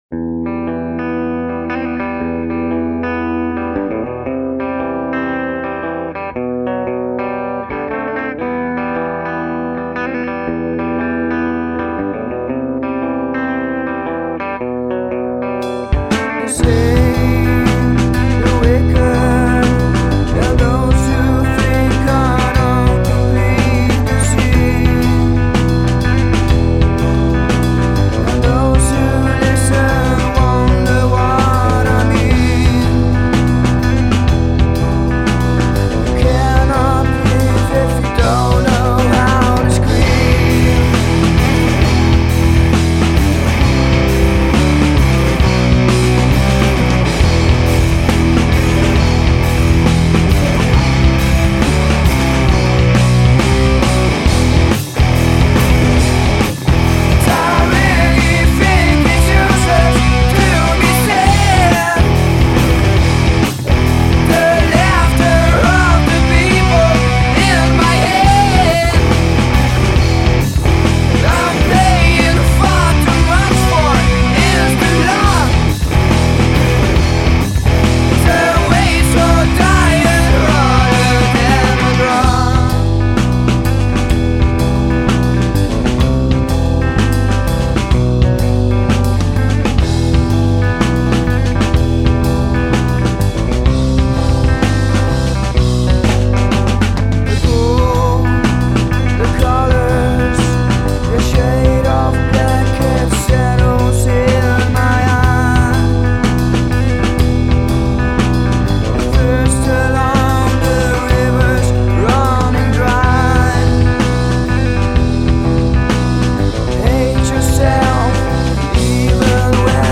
vocals and guitar
drums
bass